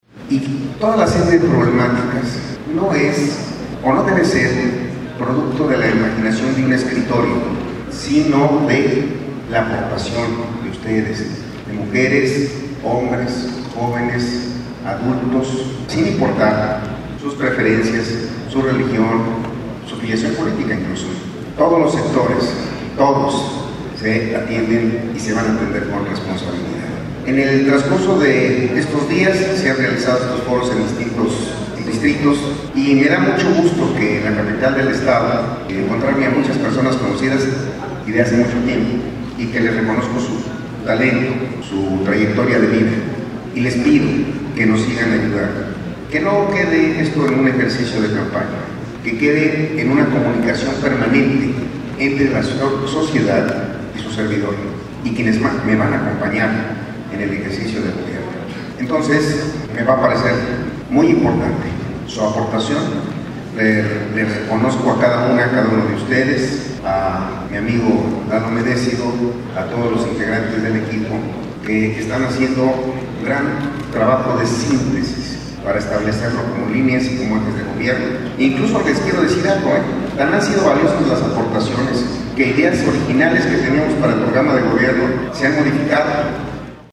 Durante el encuentro “Diálogos para la transformación de Hidalgo”, que se llevó a cabo en el Salón Perla, en Pachuca, Menchaca Salazar comentó que se han realizado diversos foros para abordar temas en materia de salud, economía, desarrollo social, educación, inclusión, gobierno, infraestructura, medio ambiente, así como igualdad de género y jóvenes.